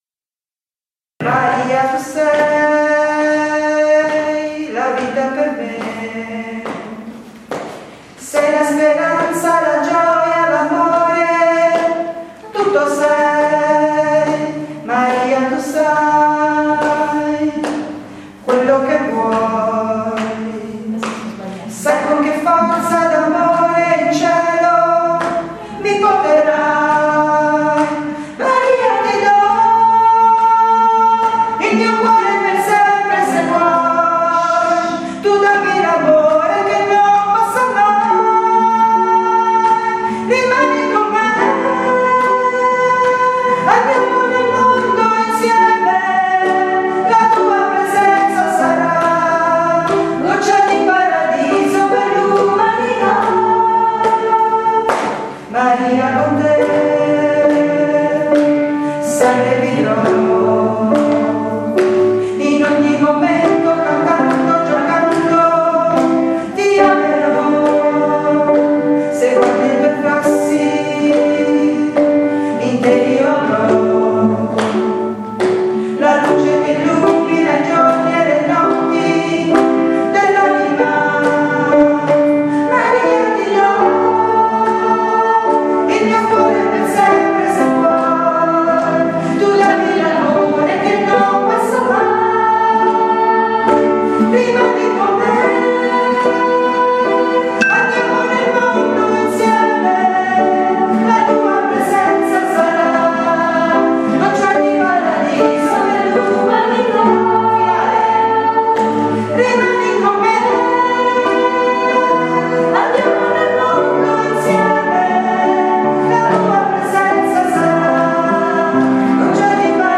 BRANI PER IL CORO LITURGICO DA STUDIARE
maria_tu_sei_soprani.mp3